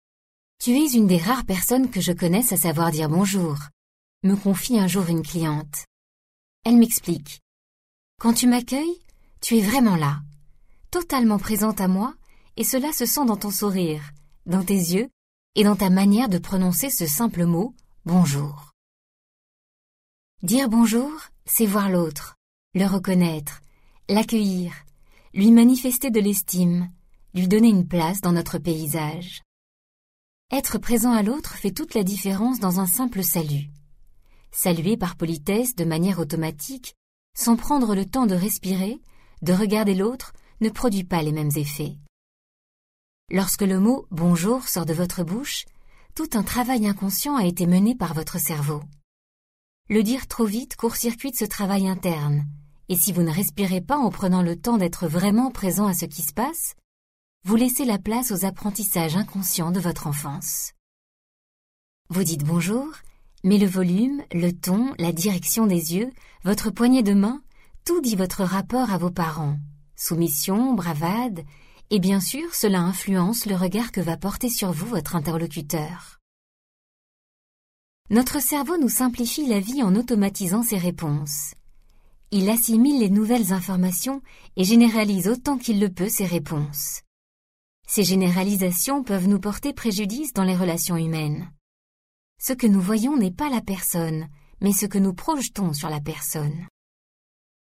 Vive et toute en empathie, la voix de la narratrice vous amènera à améliorer de suite votre façon de communiquer.